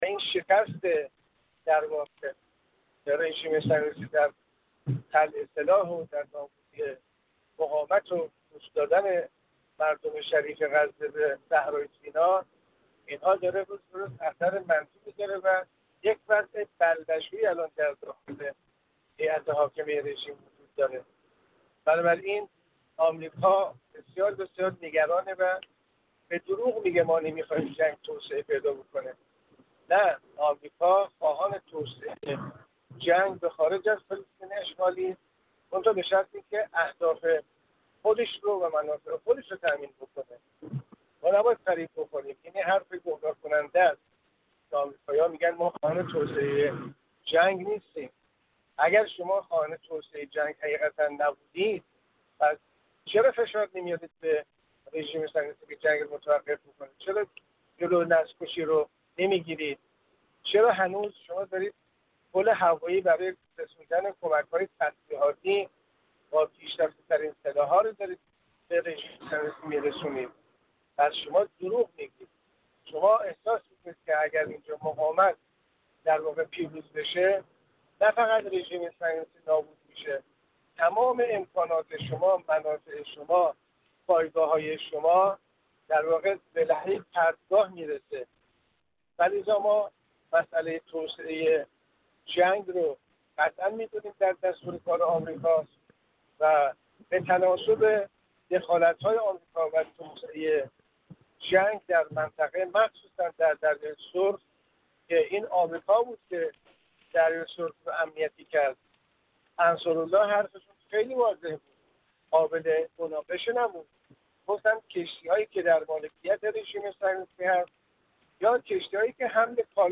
کارشناس حوزه غرب آسیا
گفت‌وگو